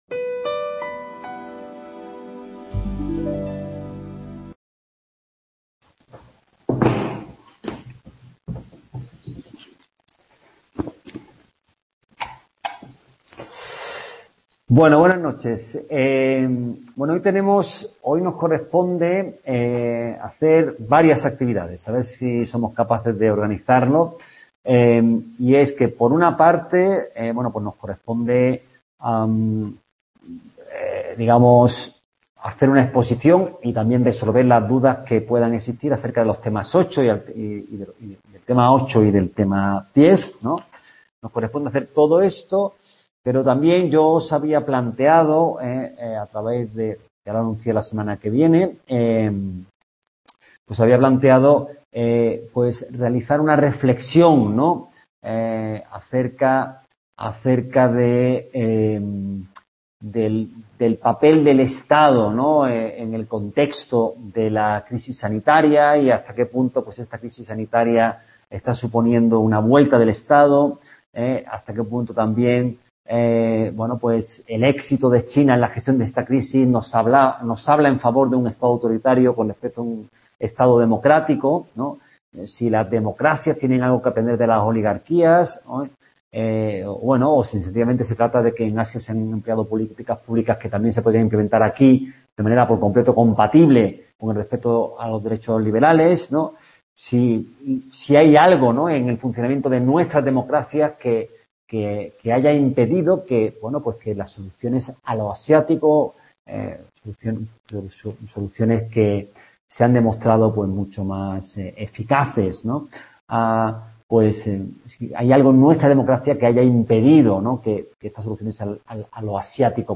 Tema 2 (Capítulo 8 ) (en diferido)